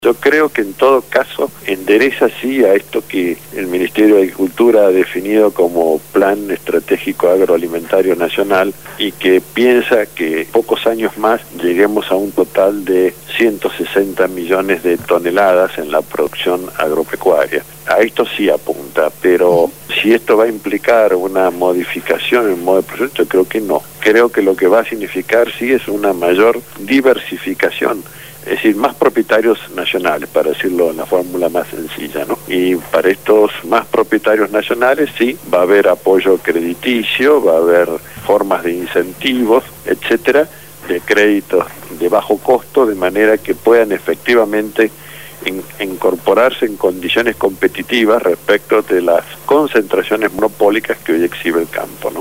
ABOGADO CONSTITUCIONALISTA